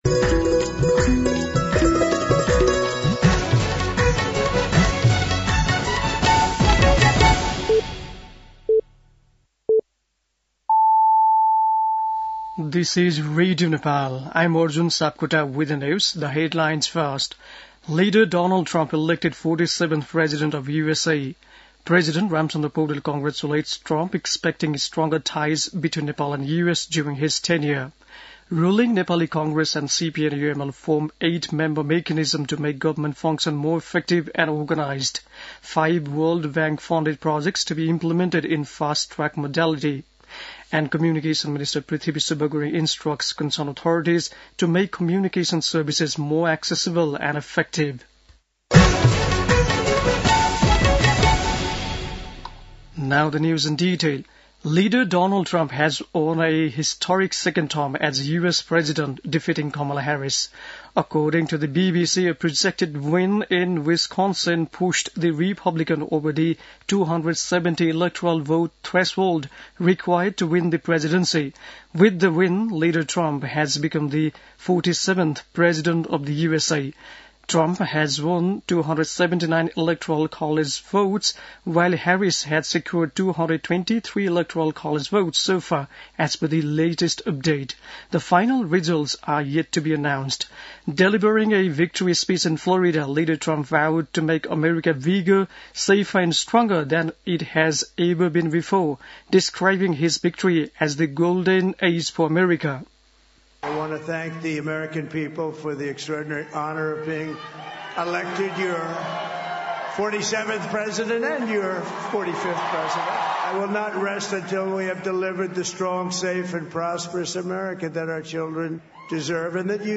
बेलुकी ८ बजेको अङ्ग्रेजी समाचार : २२ कार्तिक , २०८१
8-PM-English-NEWS-7-21.mp3